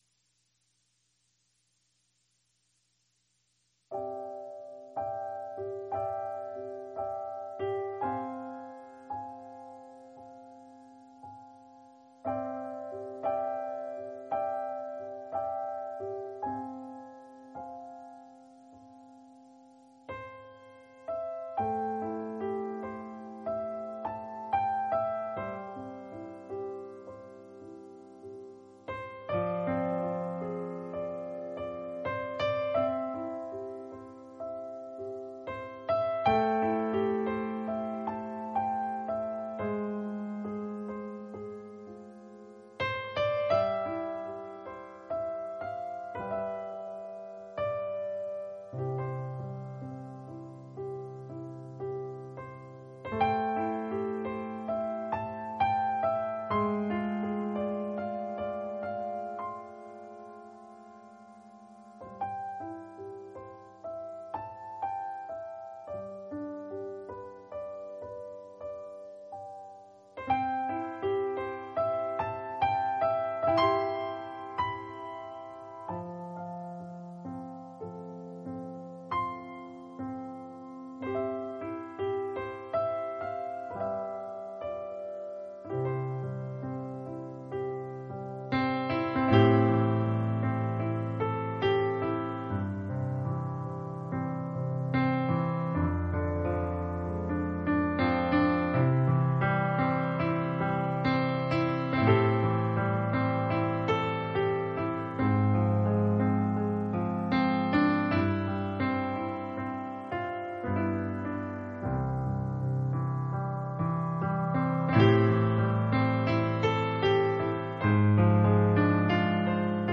An expository teaching on 1 Samuel 17, with particular focus on discovering the source of David’s conviction, and how this applies to us.